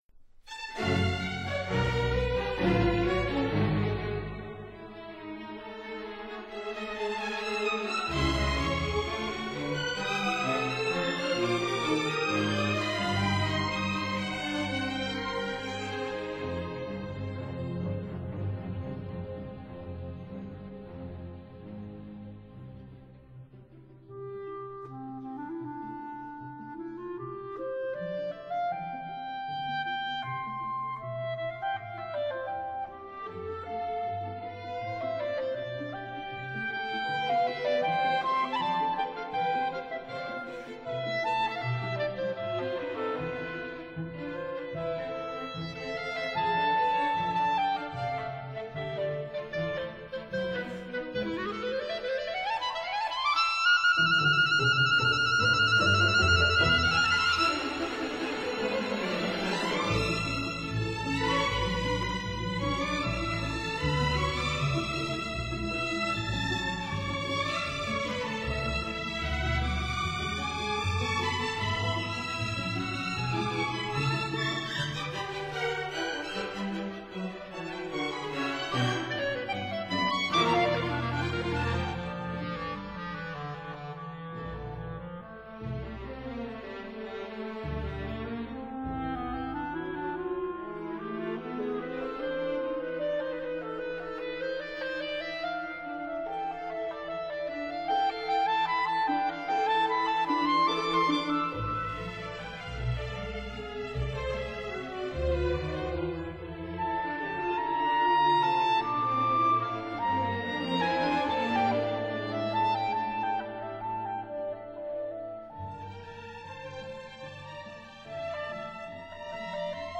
clarinet & conductor